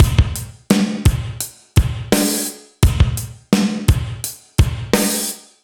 Index of /musicradar/80s-heat-samples/85bpm
AM_GateDrums_85-01.wav